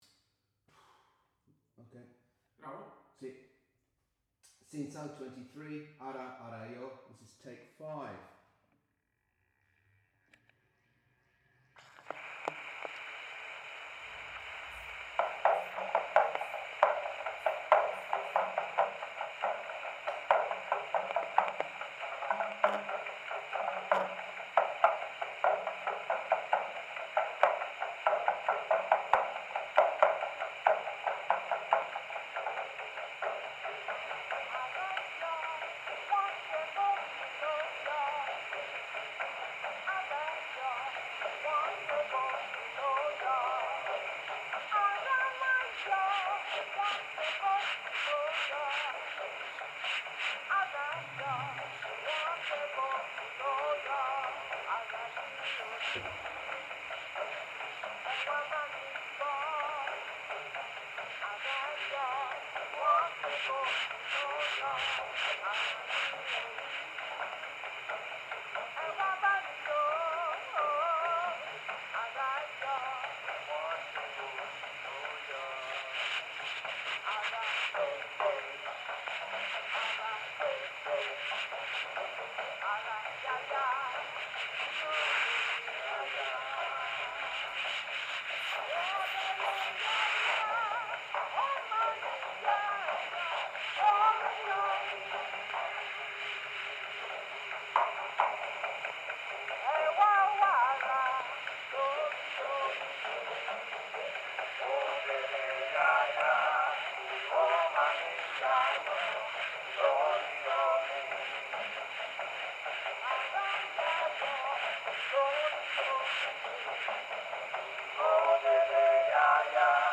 Original sound from the phonographic cylinder.